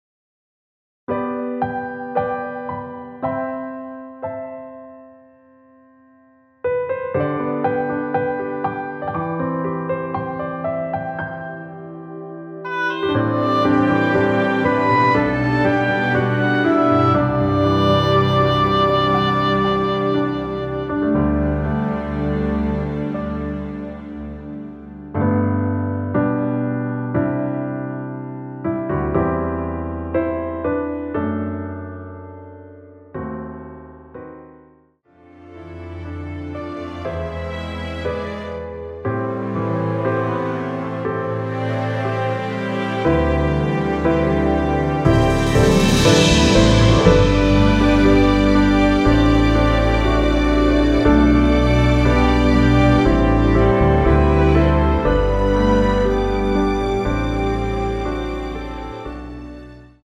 원키(1절앞+후렴) MR입니다.
앞부분30초, 뒷부분30초씩 편집해서 올려 드리고 있습니다.